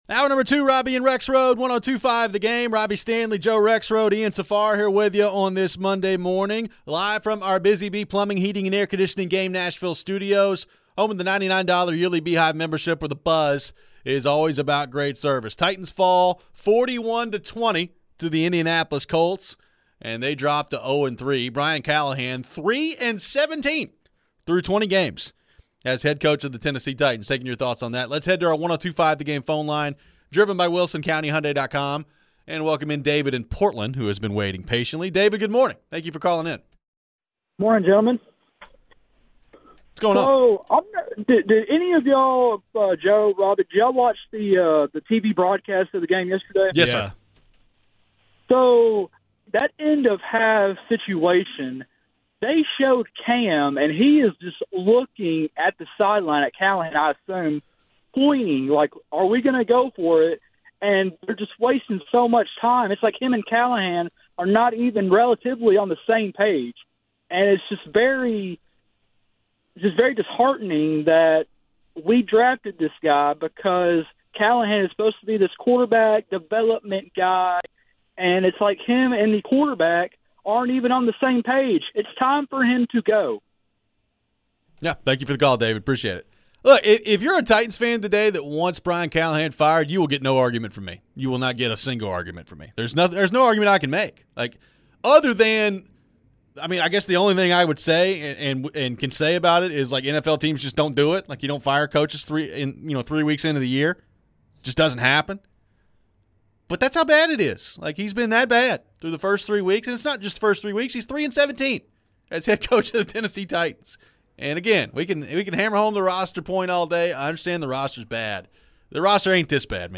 How worried should ownership be about the lack of attendance at the games? We take plenty more phones.